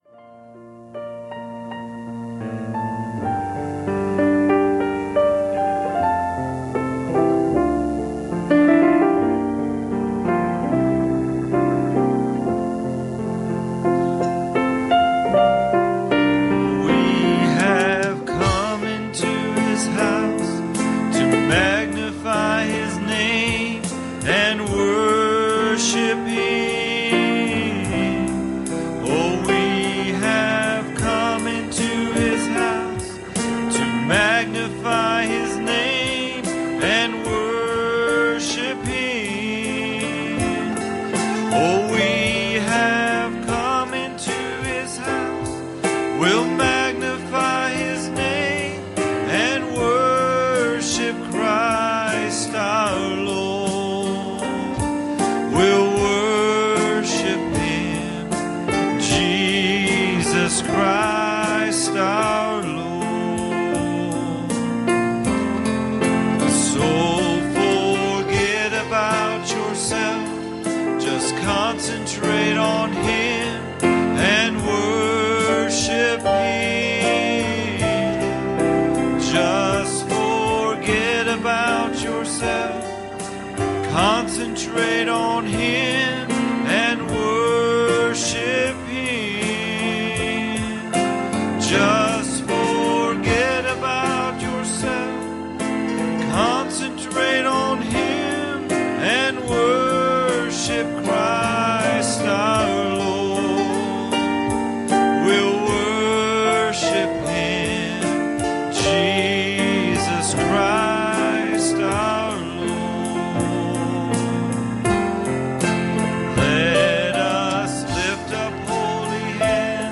Passage: 1 Kings 19:2 Service Type: Wednesday Evening